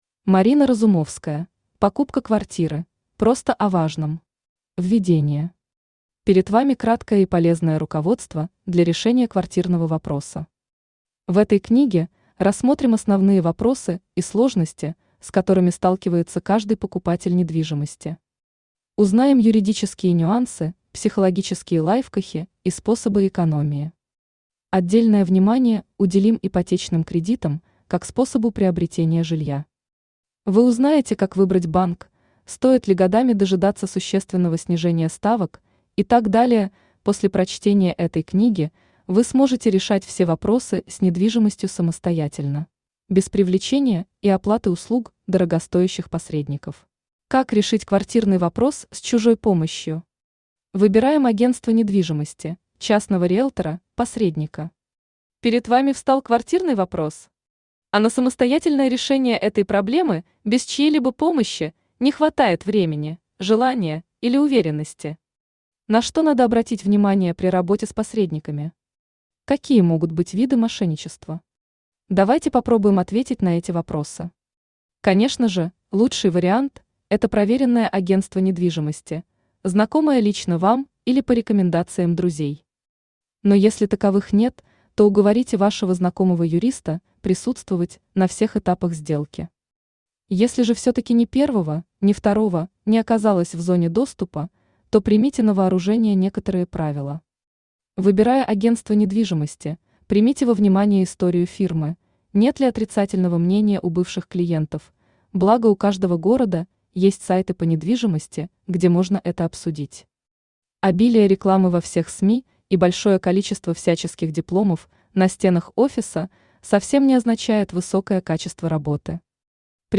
Аудиокнига Покупка квартиры. Просто о важном | Библиотека аудиокниг
Просто о важном Автор Марина Разумовская Читает аудиокнигу Авточтец ЛитРес.